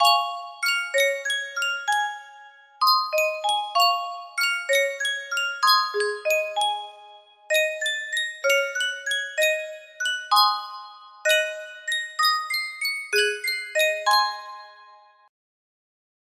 Sankyo Music Box - Take Me Out to the Ball Game HY music box melody
Full range 60